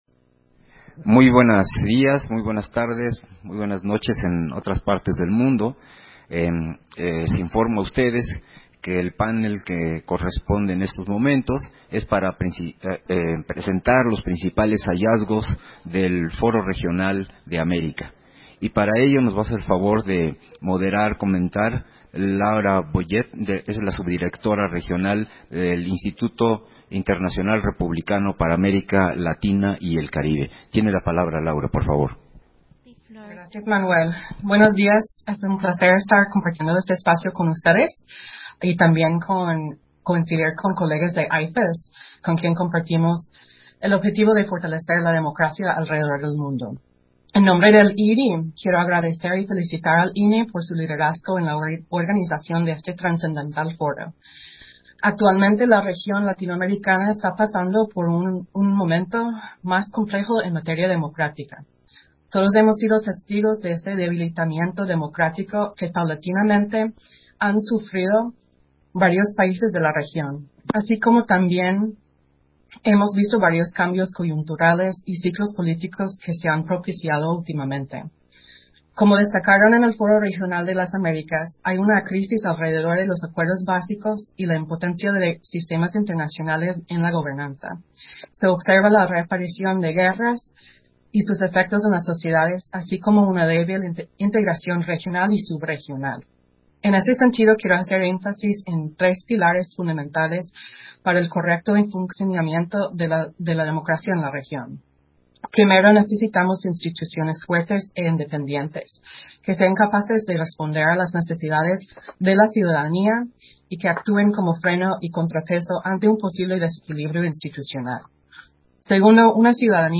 Versión estenográfica del panel: Principales hallazgos de los foro regionales, en el marco del tercer día de la Cumbre Global de la Democracia Electoral